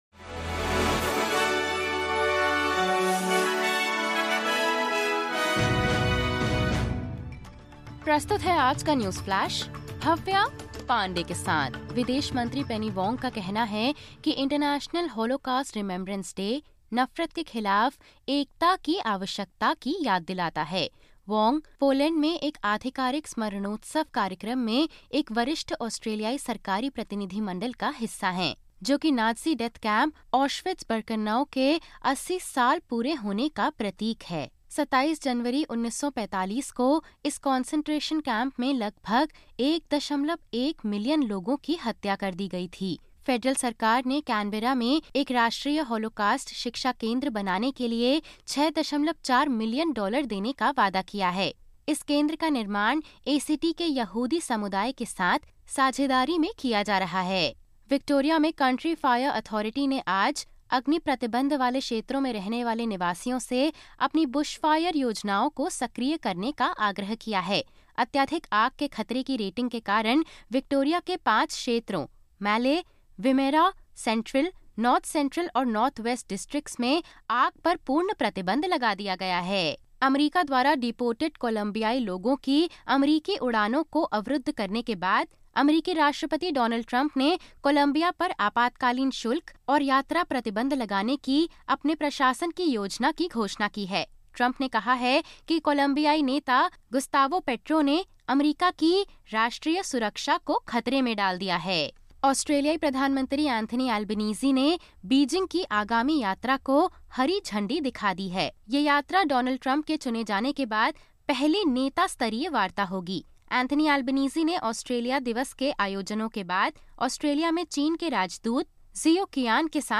Listen to the top News of 17/02/2025 from Australia in Hindi.